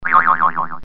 boing-spring-4_z1Fx1aNO.mp3